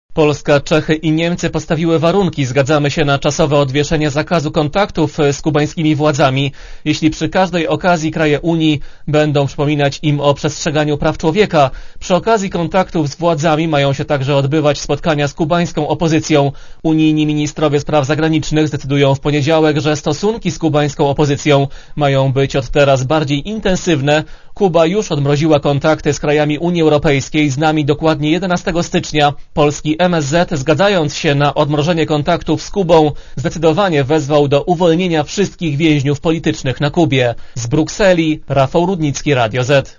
Korespondencja z Brukseli